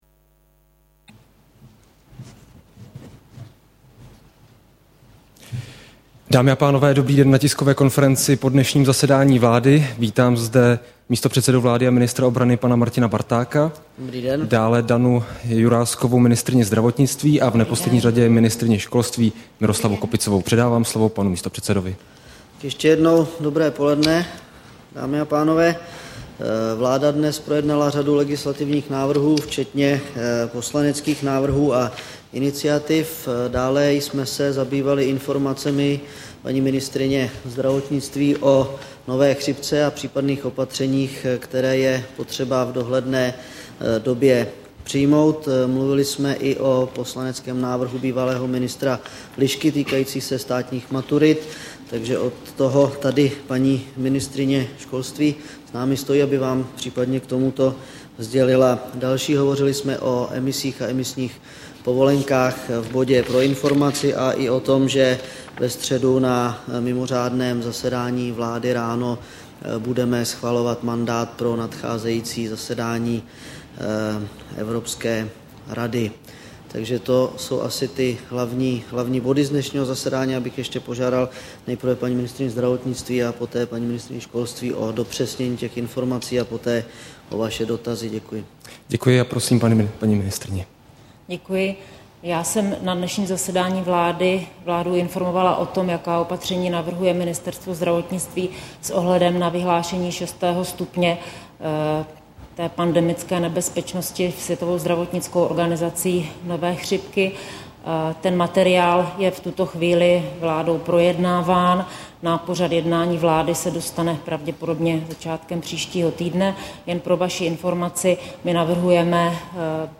Tisková konference po zasedání vlády, 15. června 2009